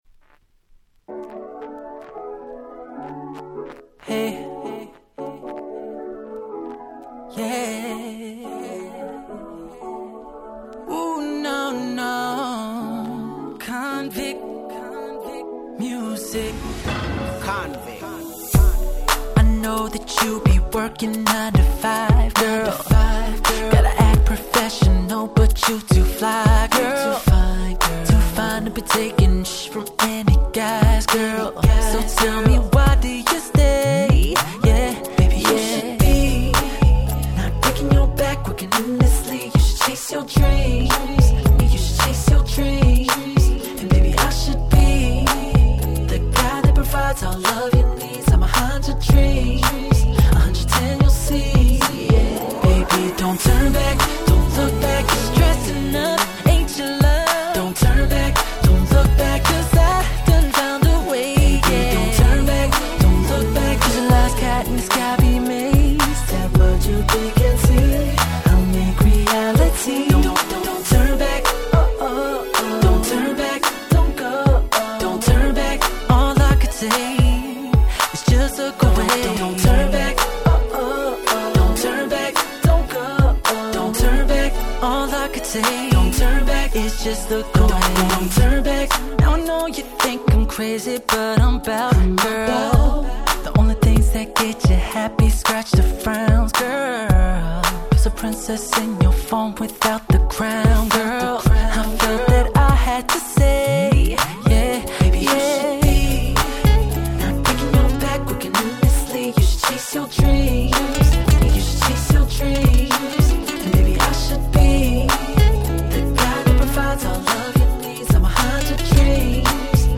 08' Big Hit R&B !!
キャッチーで使い易く、自分も何度プレイしたかわからないくらい使いました！